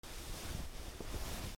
/ J｜フォーリー(布ずれ・動作) / J-05 ｜布ずれ
J｜フォーリー(布ずれ・動作)/J-05 ｜布ずれ/C414